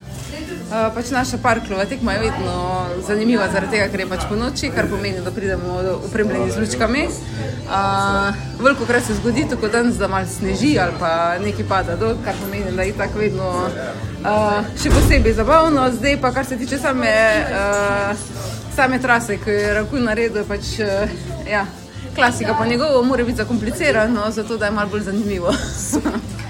Izjave udeleženk: